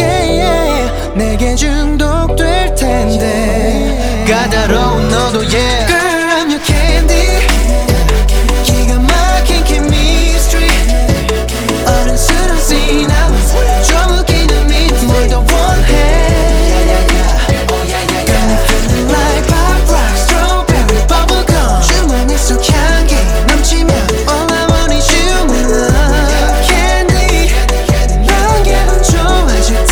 Genre: K-Pop